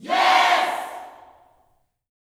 YES  01.wav